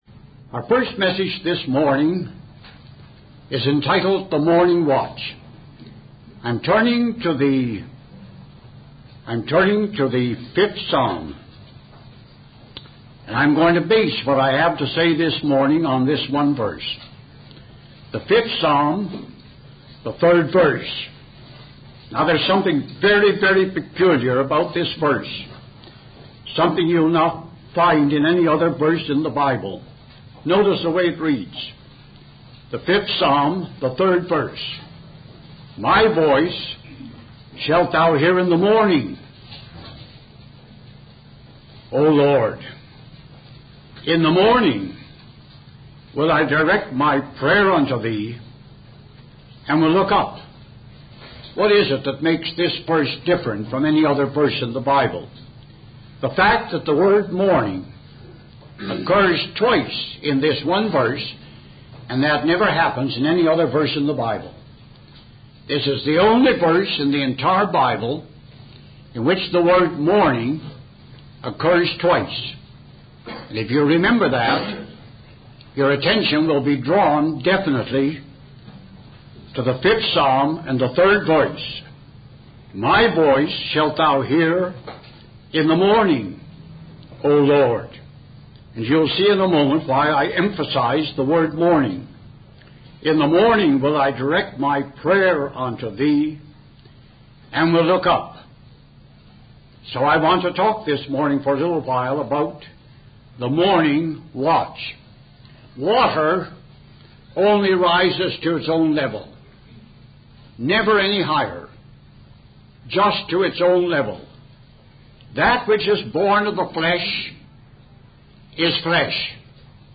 In this sermon, the speaker discusses the importance of the morning watch, which is a dedicated time of studying the Word of God and engaging in prayer. The speaker emphasizes that failure and weakness in the Christian life can be attributed to neglecting the morning watch.